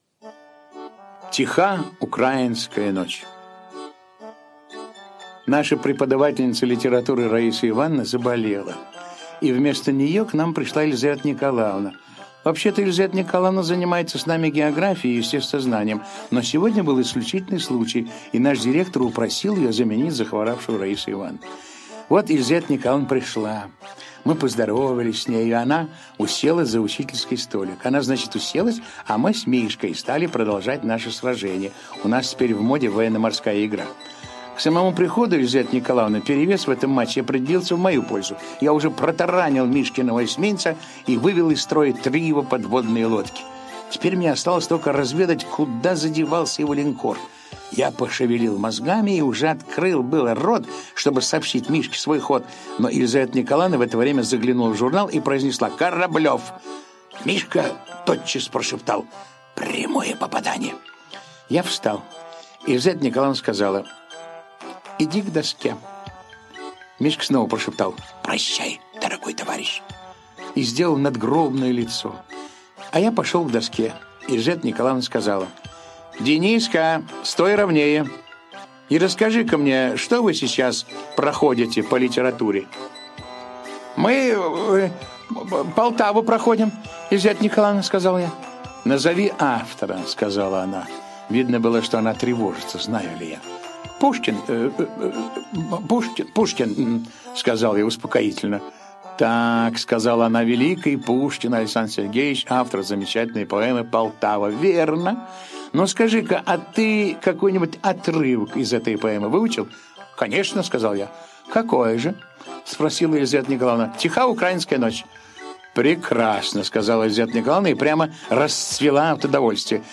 Аудиорассказ «Тиха украинская ночь»
Текст читает Евгений Весник.